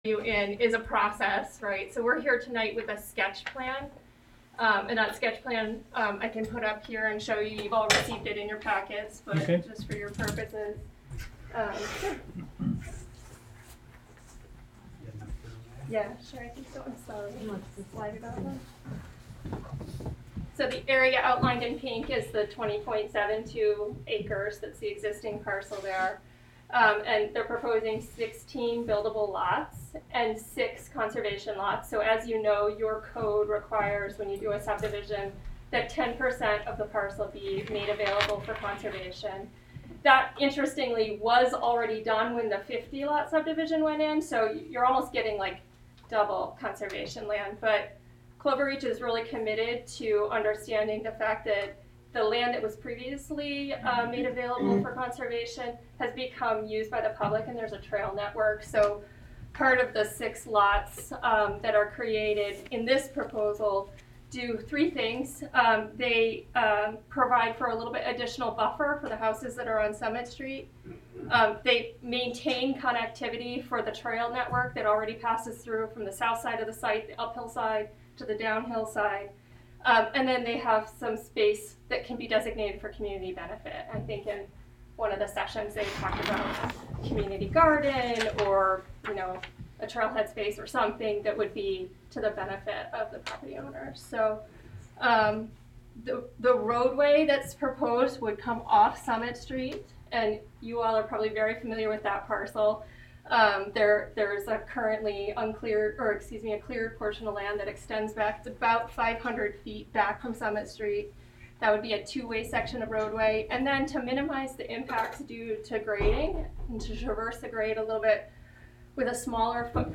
Live from the Village of Philmont: Board Meeting (Audio)
Live from the Village of Philmont: Board Meeting (Audio) Nov 16, 2021 shows Live from the Village of Philmont Live stream of the Village of Philmont public meetings.